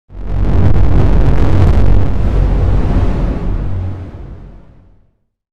Fakeout SFX for A-60. (plays randomly during the Rooms)